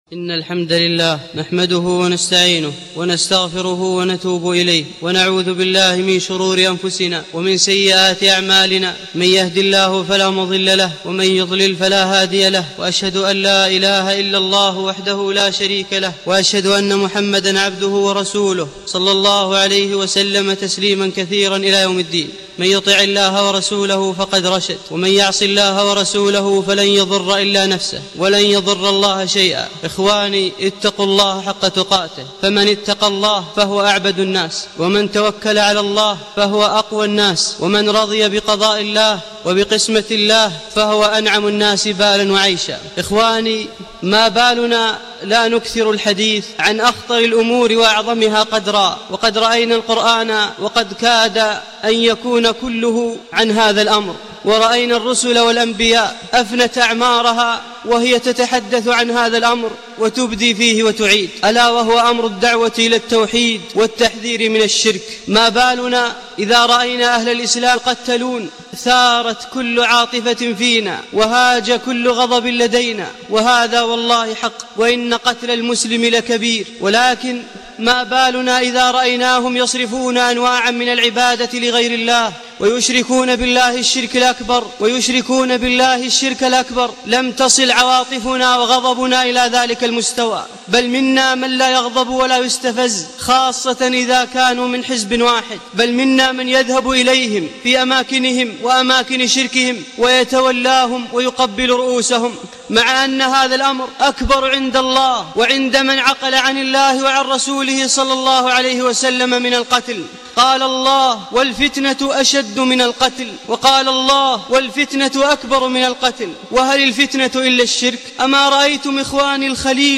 خطبة الجمعة بعنوان اضاعة التوحيد